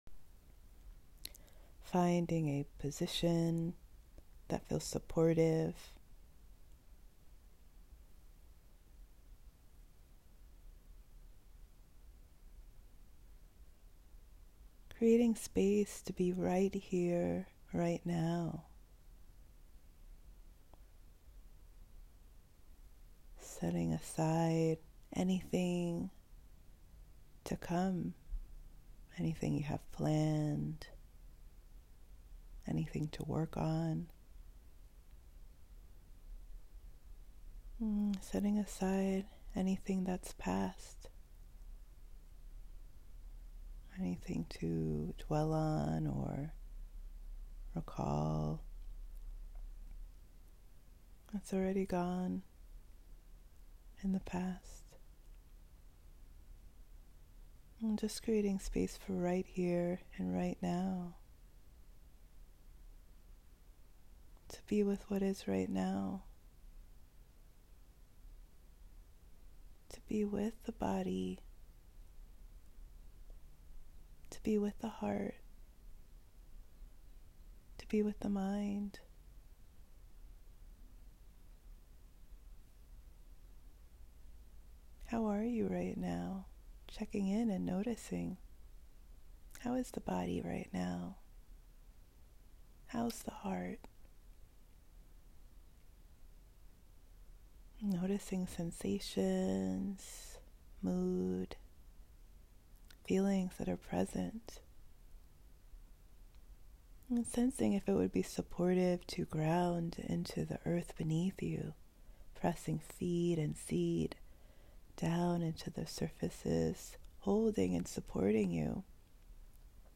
Supportive Practices + Guided Meditation
ehm-guided-allow-receive.m4a